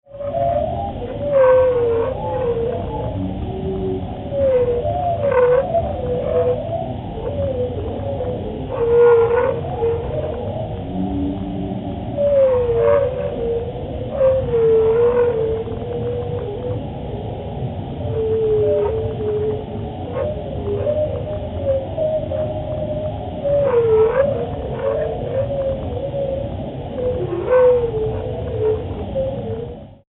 A humpback whale in the Silver Banks, Dominican Republic.
Humpback Whales in the Silver Banks, Dominican Republic
humback-whales-2.mp3